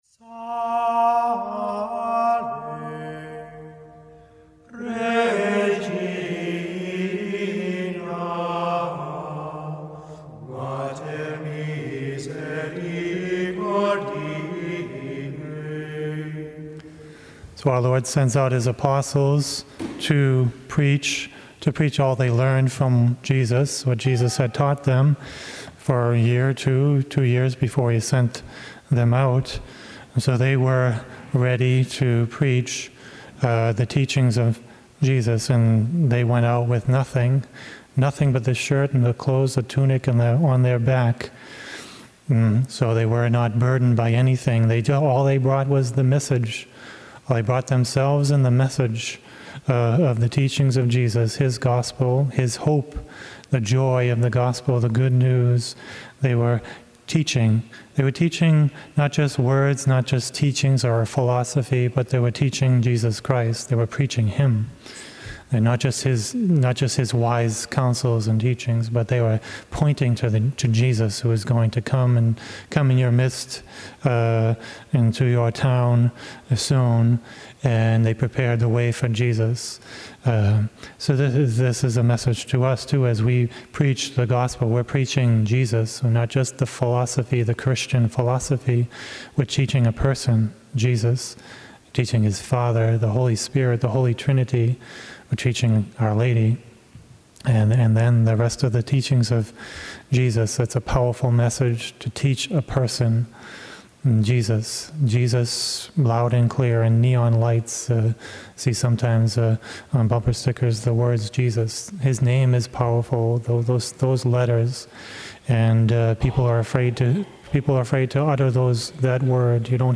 Mass: 15th Sunday in Ordinary Time - Sunday - Form: OF Readings: 1st: amo 7:12-15 Resp: psa 85:9-10, 11-12, 13-14